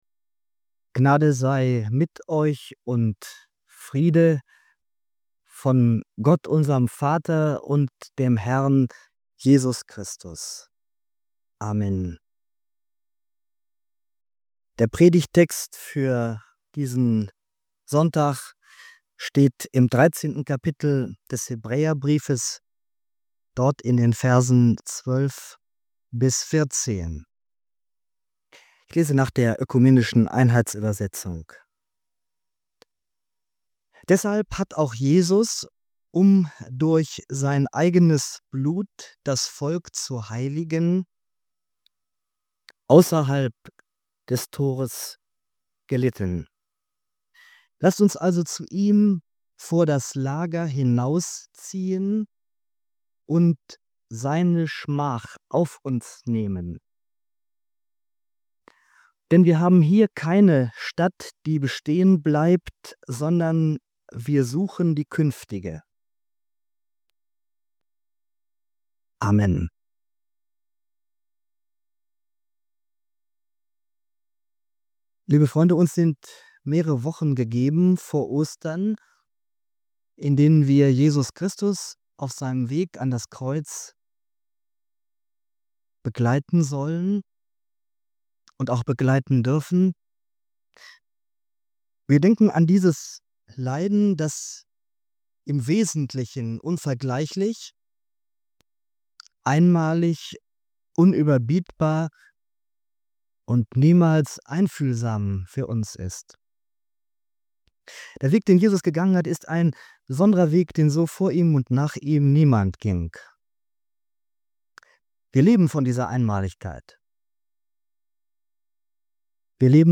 In dieser Predigt zu Hebräer 13,12–14 geht es um den Weg mit Jesus „hinaus vor das Lager“ – weg vom Applaus der Menge hin in die Nachfolge, die auch Ablehnung, Verlust von Ansehen und Einsamkeit mit sich bringen kann. Der Prediger zeigt, dass wir nicht nur von Jesu einmaligem Leiden leben, sondern auch eingeladen sind, seine Schmach mitzutragen und dadurch in eine echte Schicksalsgemeinschaft mit ihm zu kommen. Christsein bedeutet, als „Unterwegs-Seiende“ zu leben, ohne sich in dieser Welt festzusetzen, und zugleich mitten in dieser Realität verantwortungsvoll zu stehen.